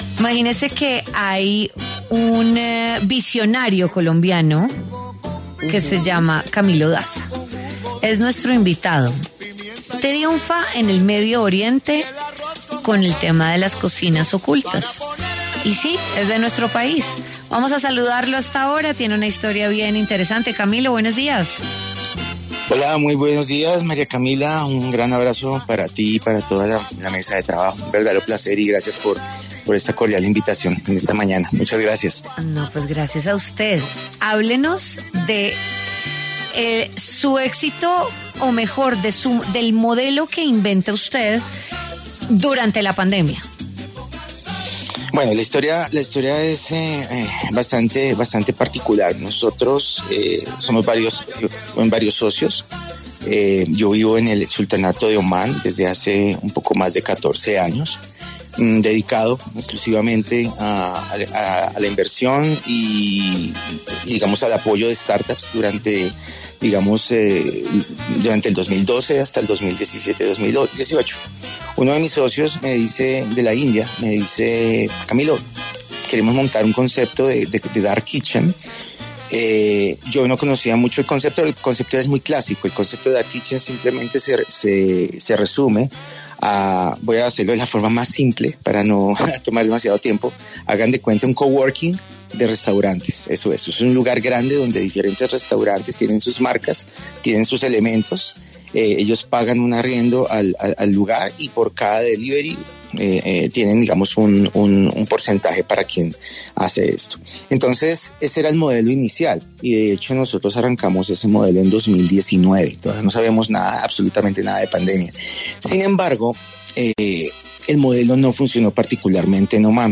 habló en W Fin de Semana sobre el millonario negocio de las cocinas ocultas.